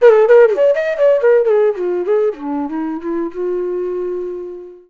FLUTE-B06 -L.wav